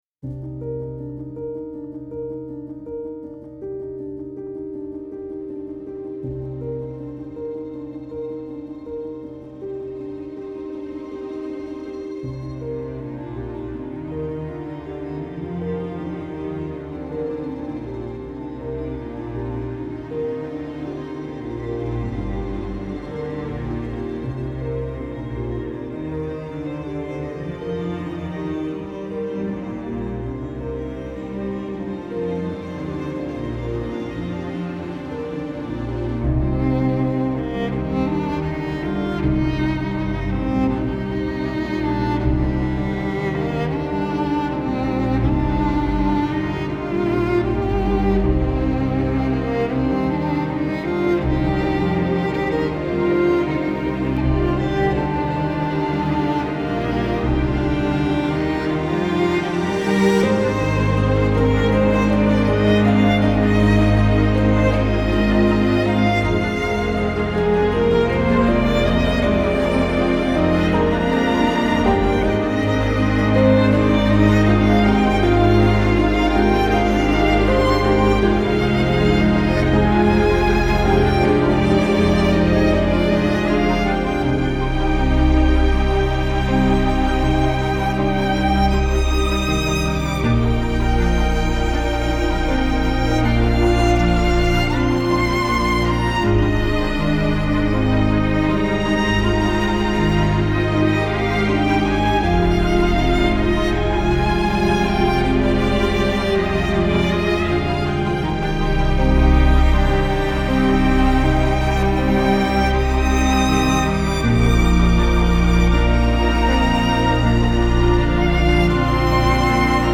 ارهو
عصر جدید , غم‌انگیز , موسیقی بی کلام